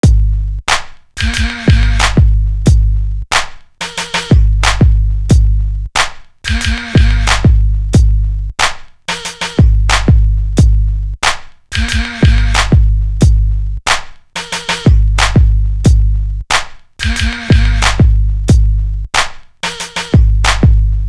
Craze Drum.wav